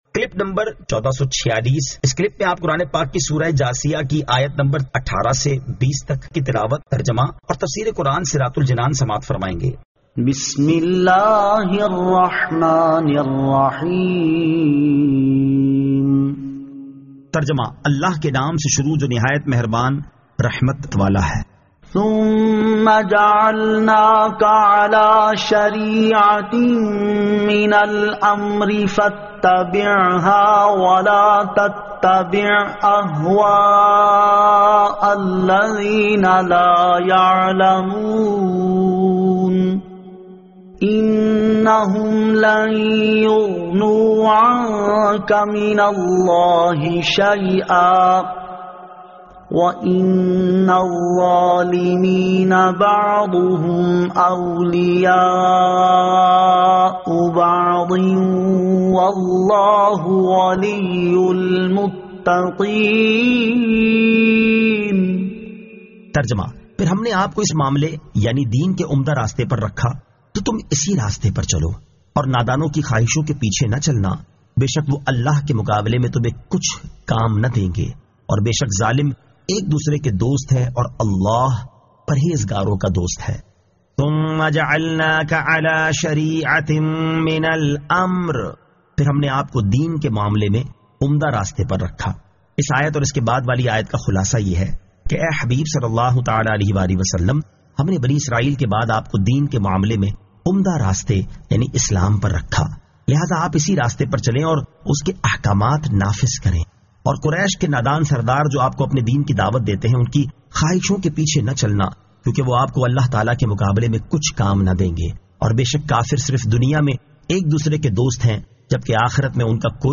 Surah Al-Jathiyah 18 To 20 Tilawat , Tarjama , Tafseer